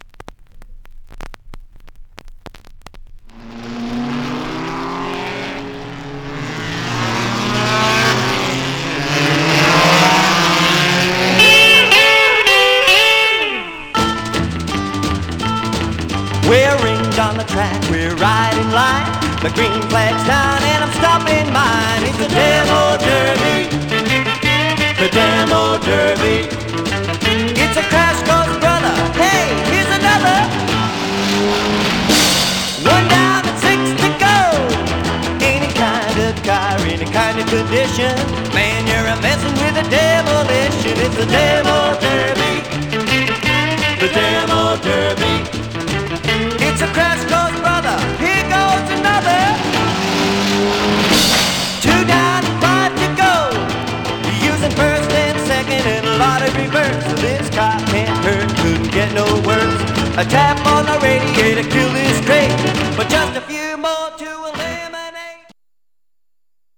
Some surface noise/wear
Mono
Surf